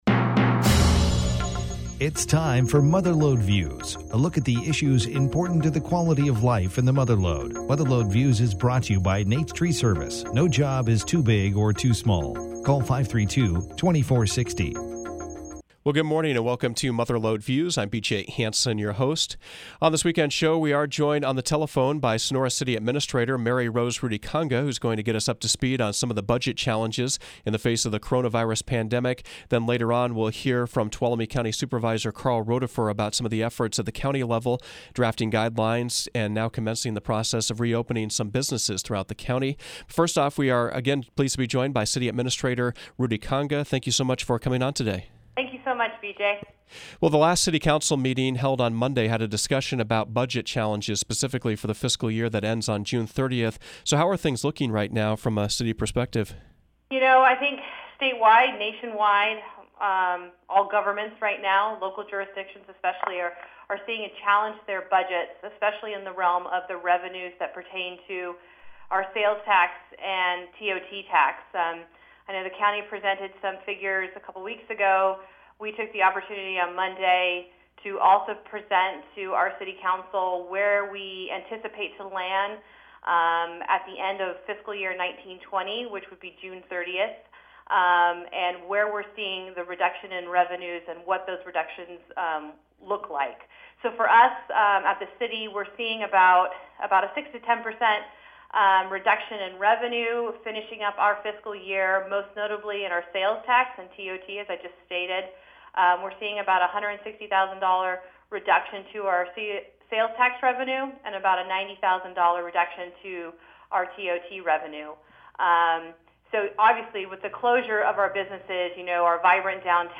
The first segment of Mother Lode Views featured Sonora City Administrator Mary Rose Rutikanga speaking about the budget impacts related to the cornonavirus pandemic and how the city plans to balance it.